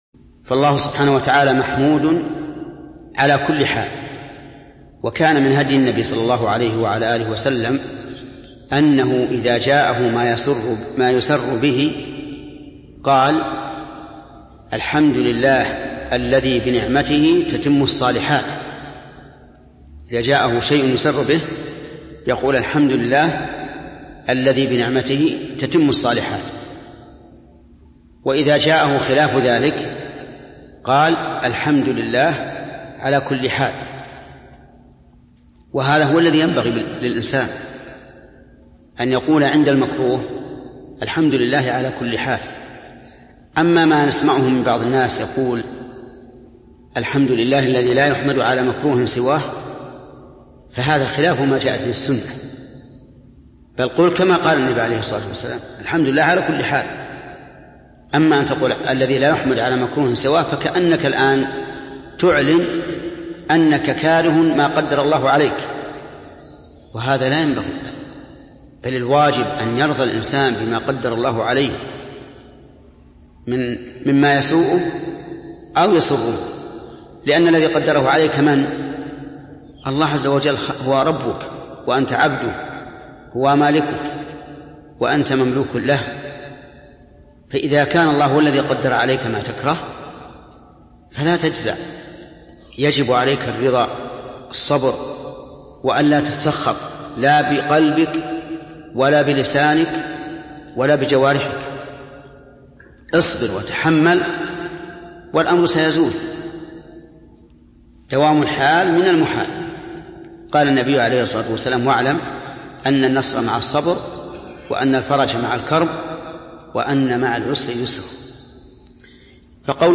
القسم: من مواعظ أهل العلم